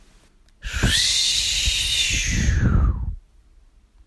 嗖嗖嗖的空气效果 " 05483 扫荡空气的嗖嗖声
描述：处理扫地ari旋风
Tag: 运动 清扫 旋风 分阶段 空气 过渡 SFX 声音